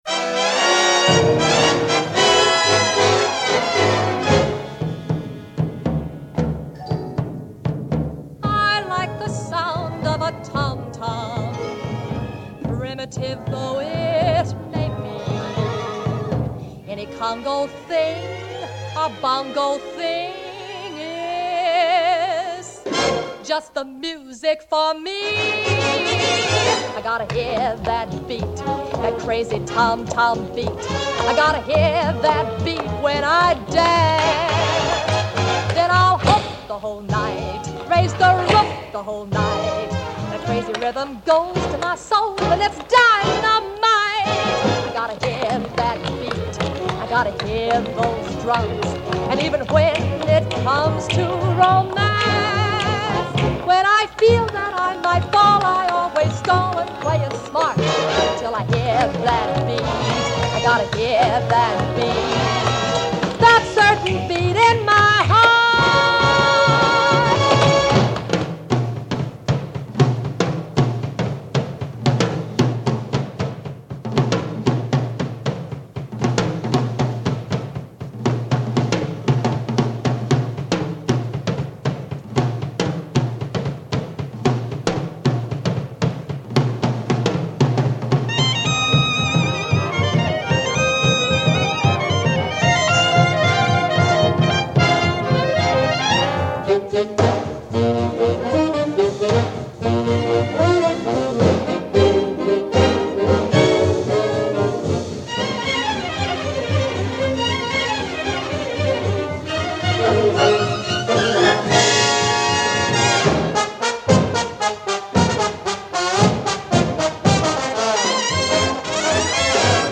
1953   Genre: Soundtrack   Artist